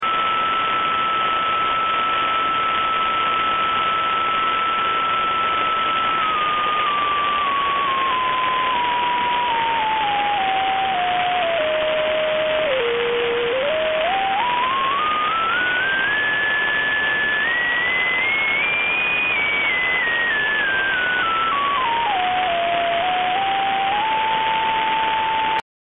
Это не смертельно, но треск как был так и остался. Приведу скриншоты динамики трансивера и аудио ролик при сигнале с ГСС всего 0.4 мкВ.
При больших уровнях треска не слышно. При замерах не использовались никаких УВЧ и аттенюаторов (ГСС> полосовики> трансивер).